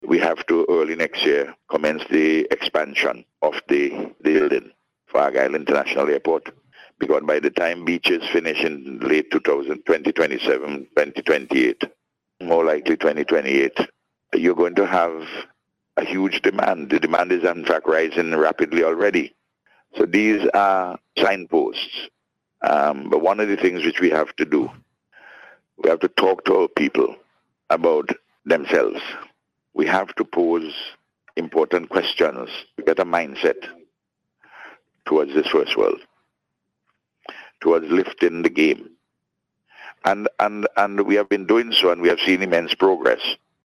Prime Minister Dr. Ralph Gonsalves announced plans for the expansion while speaking on radio recently.